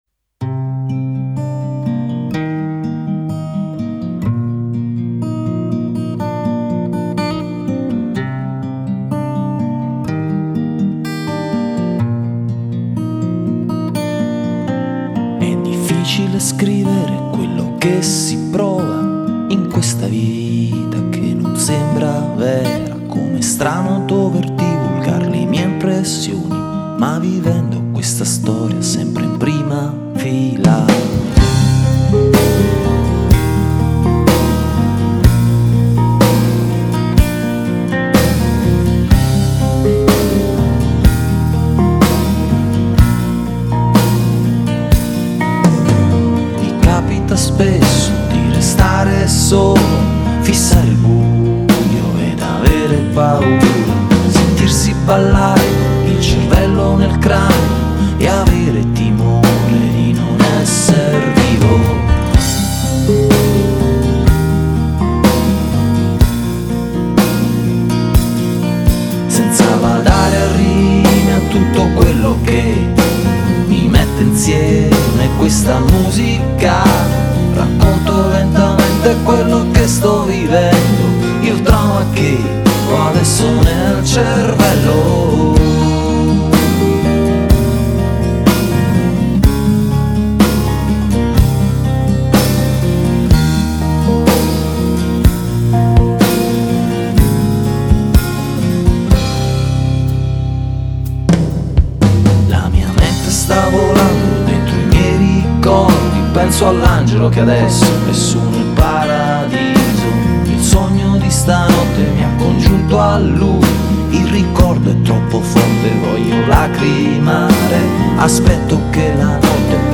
GenereWorld Music / Folk